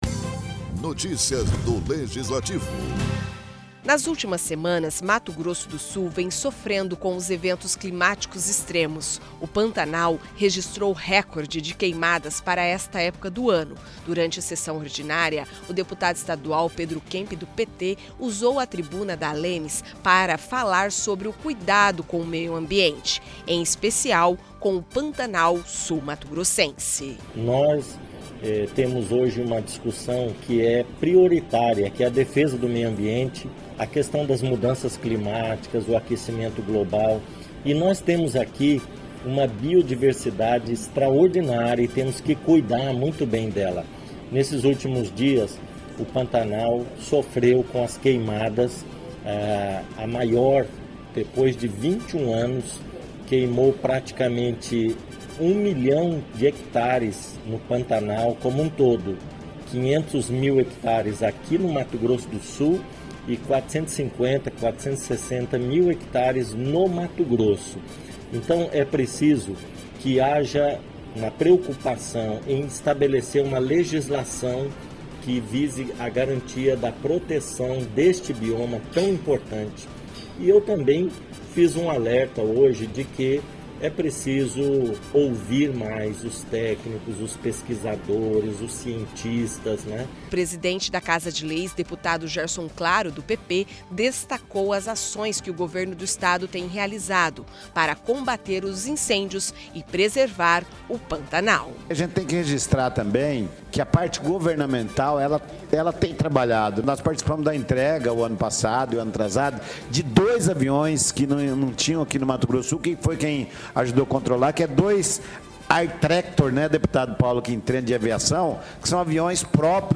Durante sessão, deputados falam sobre incêndios no Pantanal